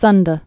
(sŭndə, sn-)